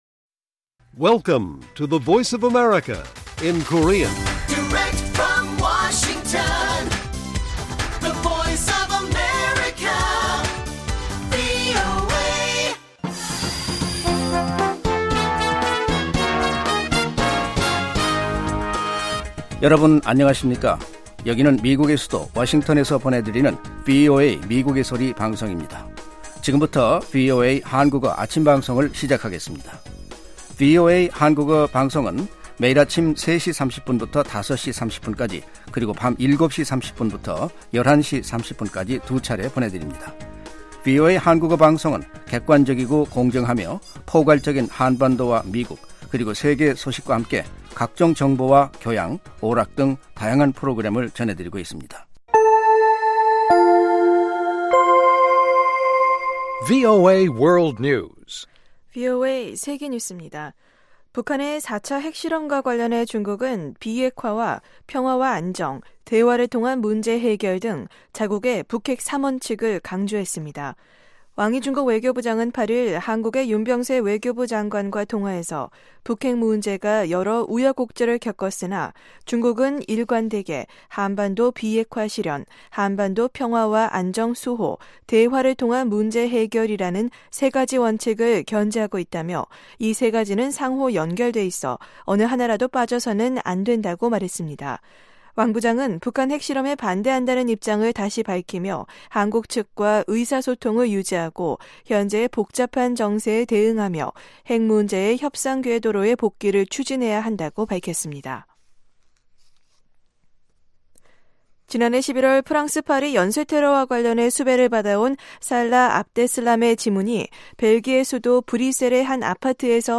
생방송 여기는 워싱턴입니다